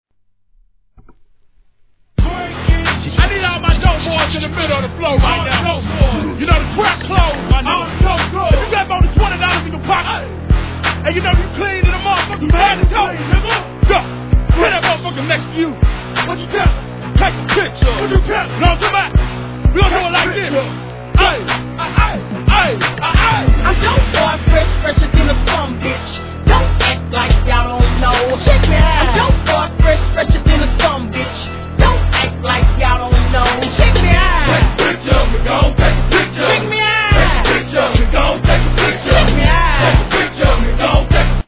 G-RAP/WEST COAST/SOUTH
強力なストリングスに負けず劣らずの豪快なラップスタイルがカッコイイです！